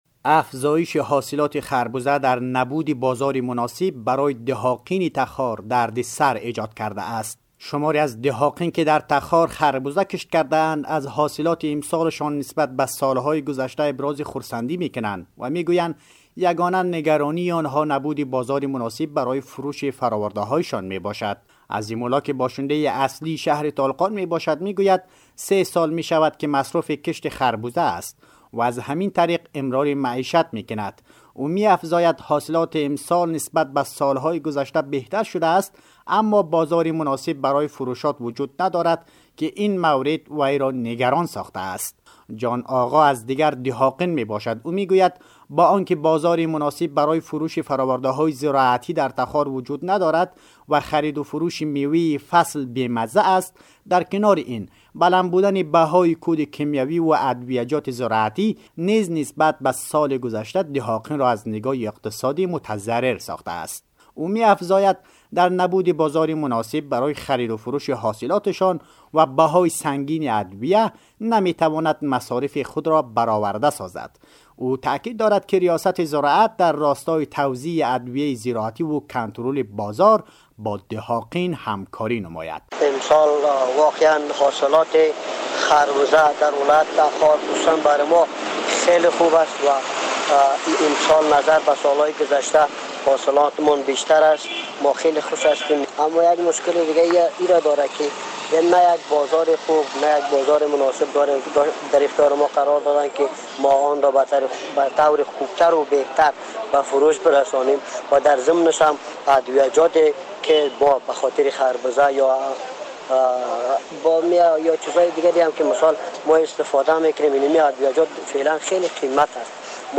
به گزارش رادیو دری؛ شماری از کشاورزان که در تخار خربزه کشت کرده‌اند، از افزایش محصولات امسال شان نسبت به سال‌های گذشته ابراز خرسندی می کنند و می گویند تنها نگرانی آنها نبود بازار مناسب برای فروش است.